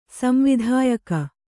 ♪ samvidhāyaka